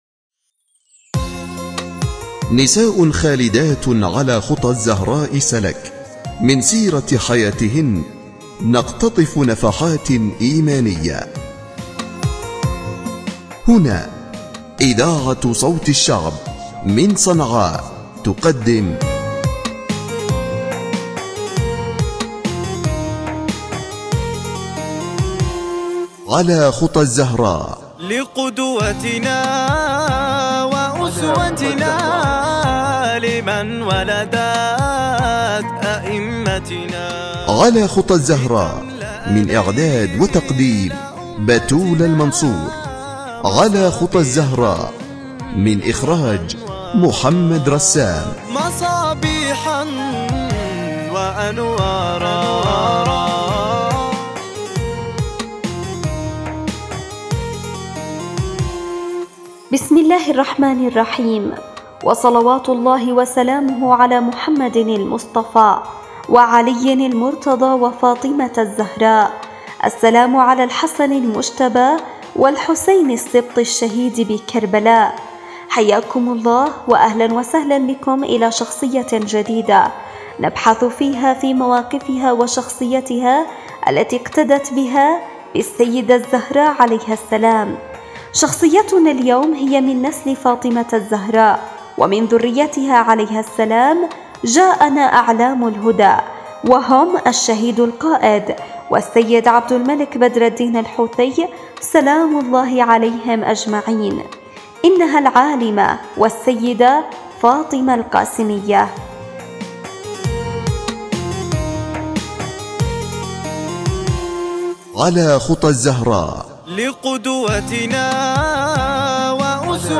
برنامج وثائقي يحكي عن سيرة الزهراء عليها السلام